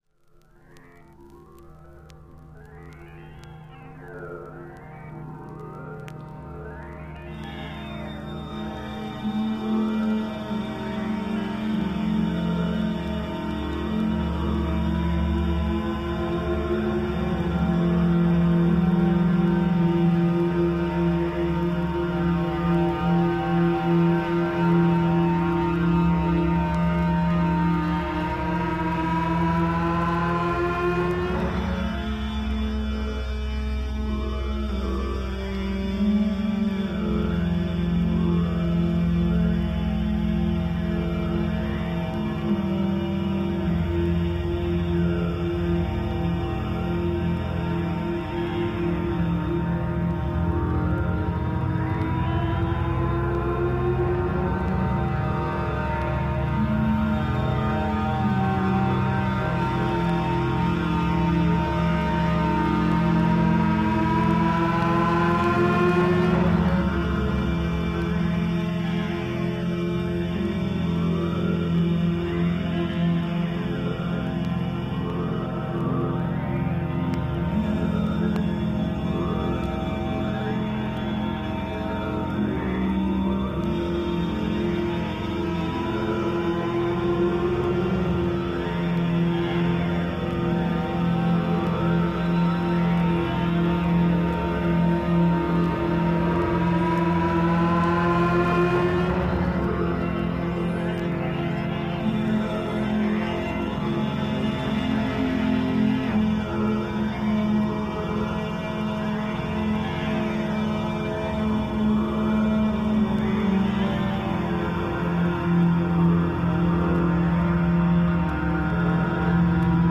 Avant-Garde Pop Psych Rock